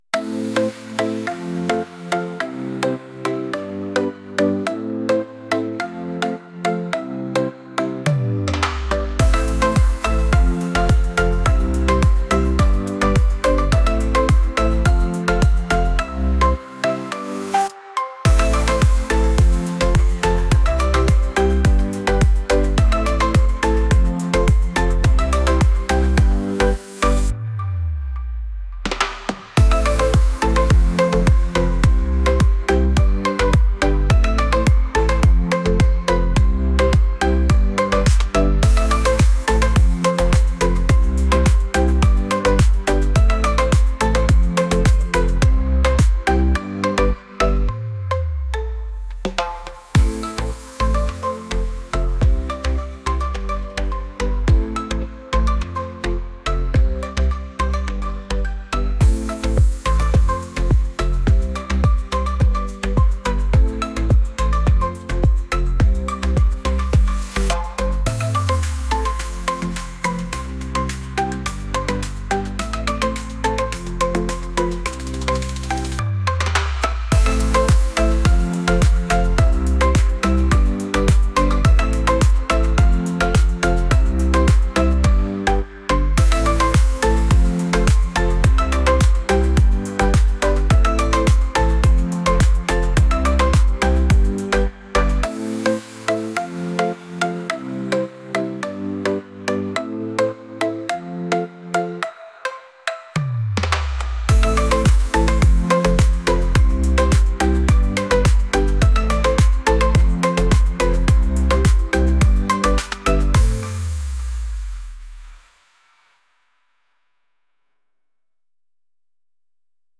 pop | catchy